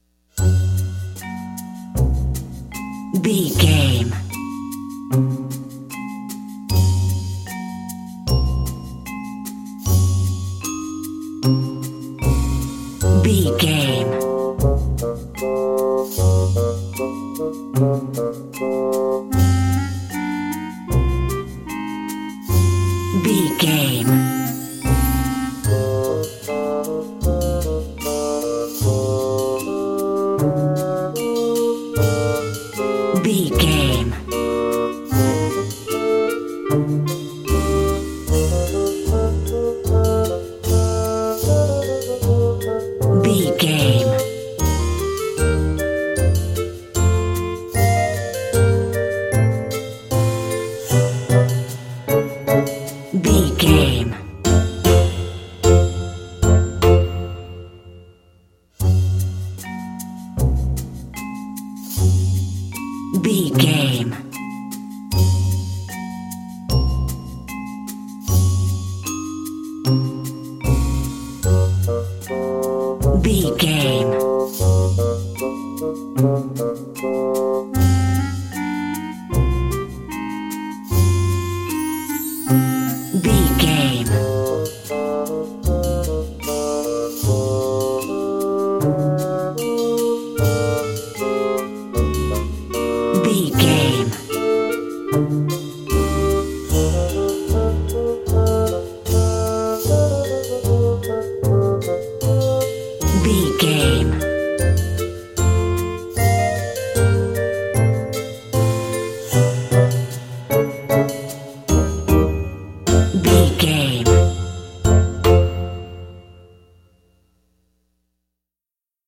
Ionian/Major
F#
Slow
orchestra
strings
flute
drums
circus
goofy
comical
cheerful
perky
Light hearted
quirky